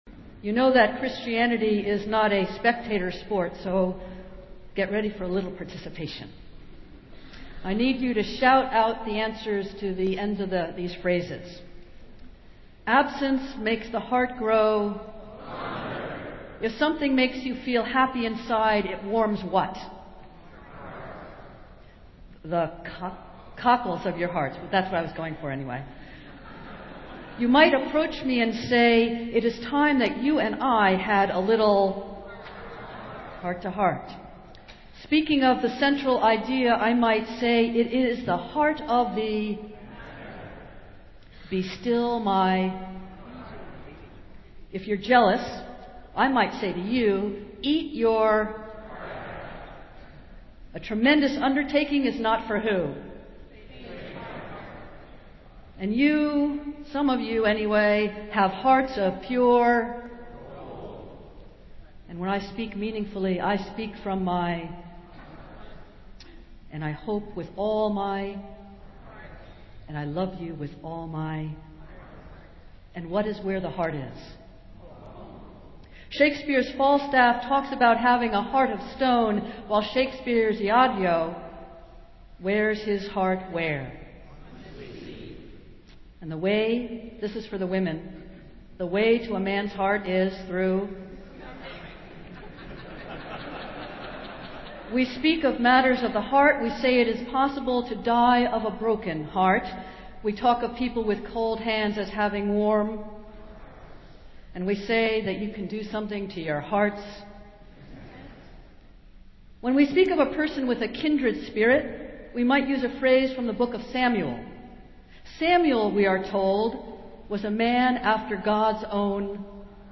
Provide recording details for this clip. Festival Worship - Mother's Day